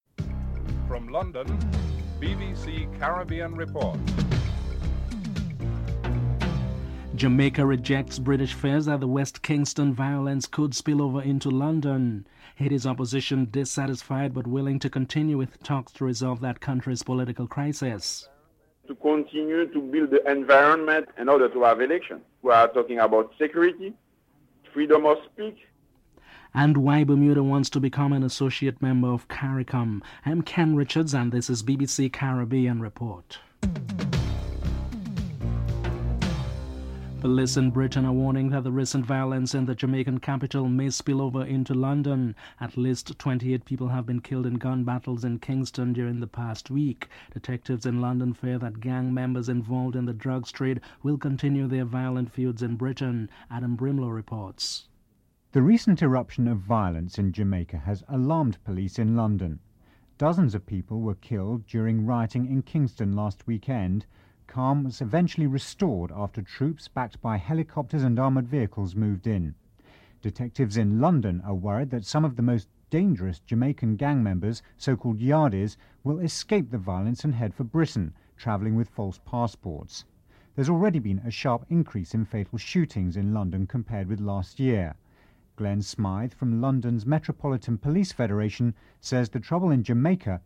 1. Headlines (00:00-00:35)
5. Why Bermuda wants to become an associate member of Caricom? Premier Jennifer Smith is interviewed (11:29-13:37)